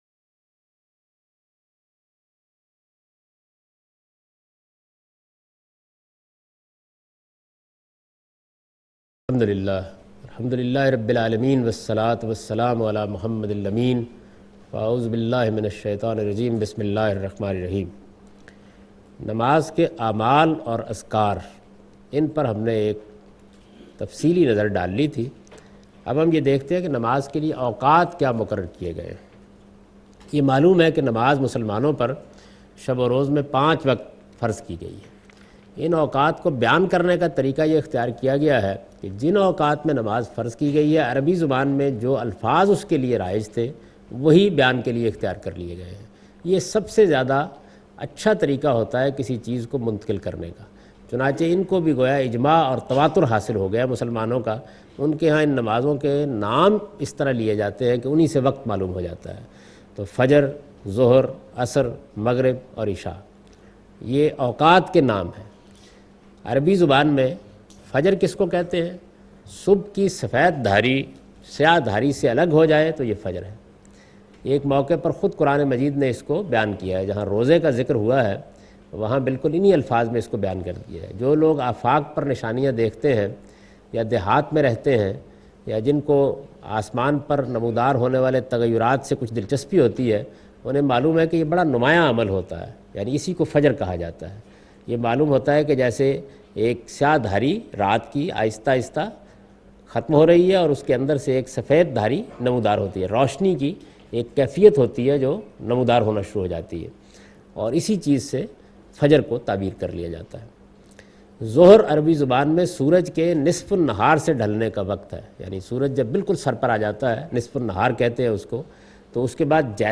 In this lecture series he teaches 'The shari'ah of worship rituals'. In this sitting he teaches prayer timings and rakat of prayer (Nimaz).